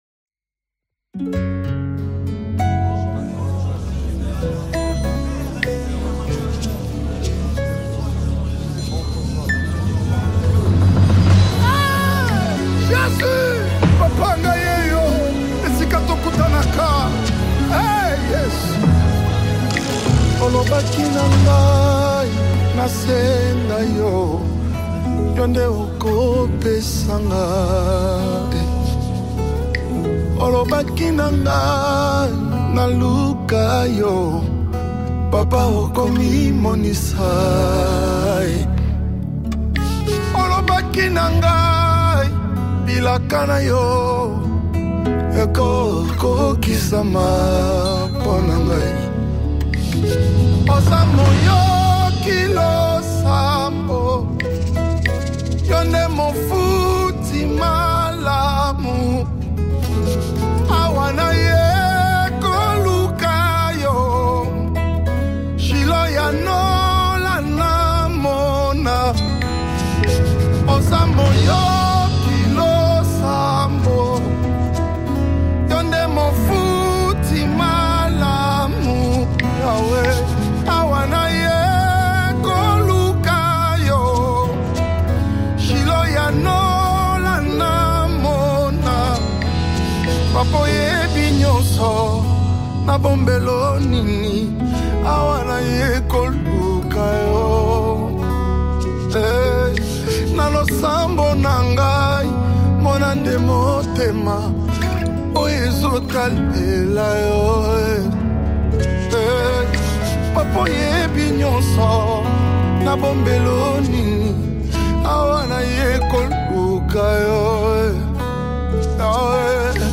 Congo Gospel Music
worship anthem
With PASSIONATE VOCALS
and a WORSHIPFUL MELODY